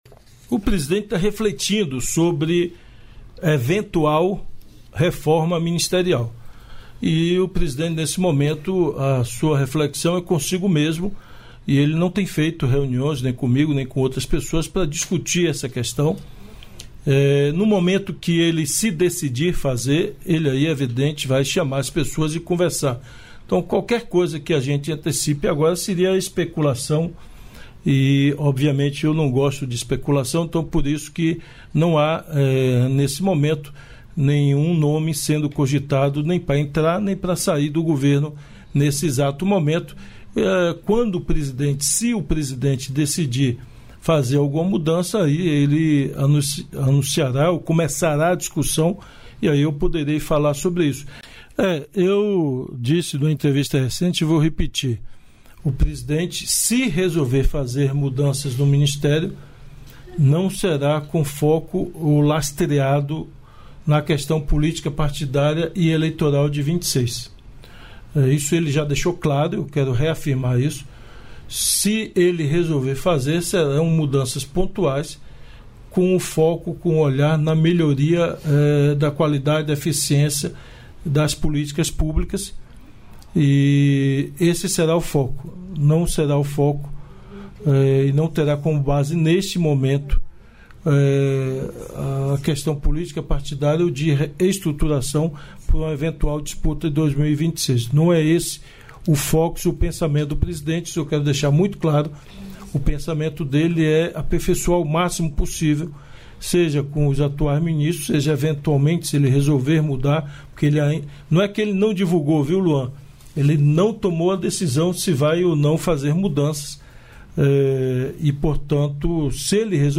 Trecho da participação do ministro da Casa Civil, Rui Costa, no programa "Bom Dia, Ministro" desta quarta-feira (22), nos estúdios da EBC, em Brasília.